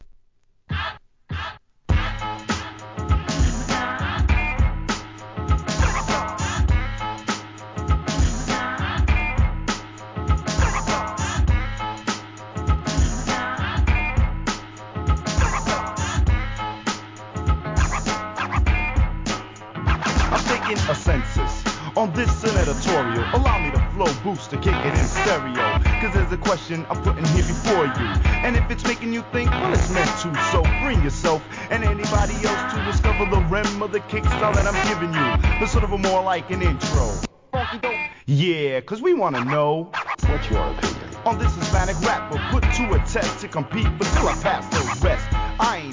HIP HOP/R&B
N.Y.産のマイナーミドル!